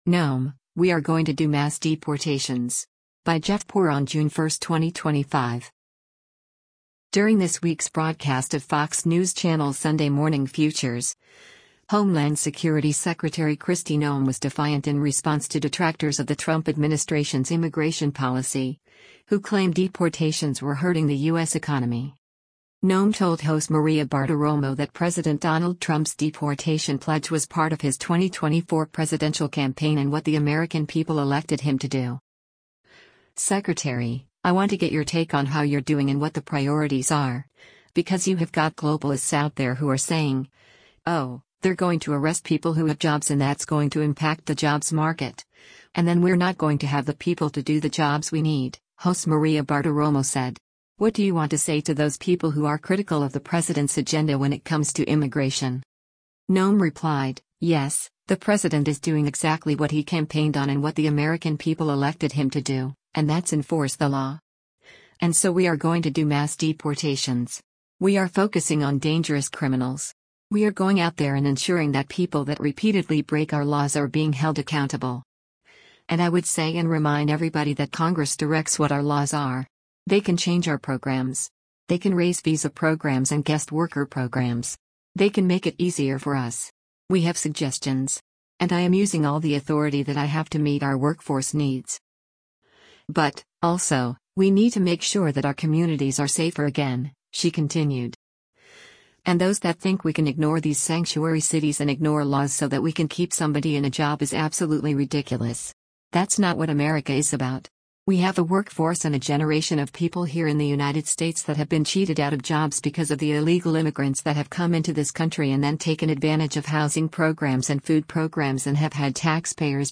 During this week’s broadcast of Fox News Channel’s “Sunday Morning Futures,” Homeland Security Secretary Kristi Noem was defiant in response to detractors of the Trump administration’s immigration policy, who claim deportations were hurting the U.S. economy.